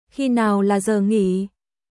Khi nào là giờ nghỉ?休憩時間はいつですか？キー ナーオ ラ ザー ンギー？